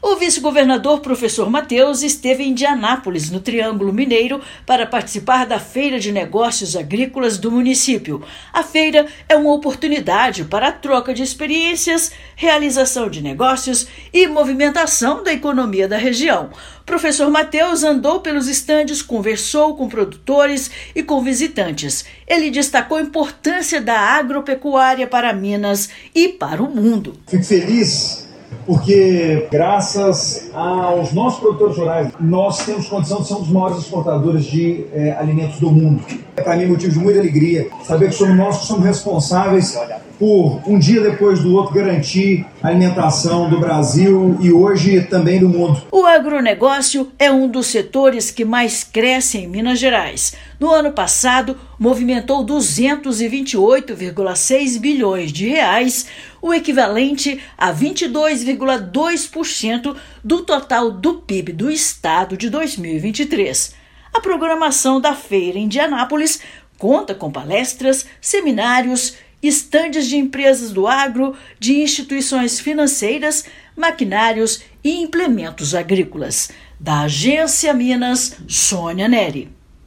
Fenaindi conta com palestras, seminários, revenda de maquinários e equipamentos agrícolas, e movimenta a economia local. Ouça matéria de rádio.